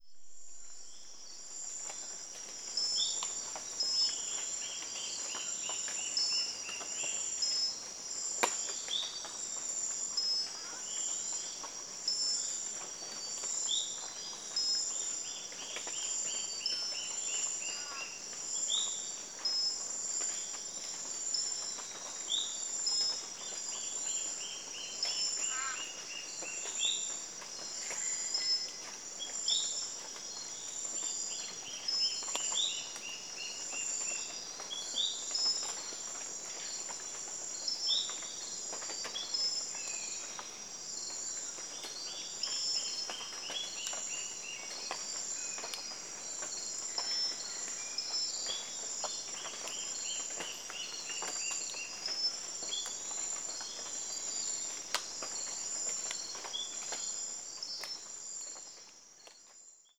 Soundbeispiel 1 aus dem Darién vom 15.06.2019 (Regenzeit).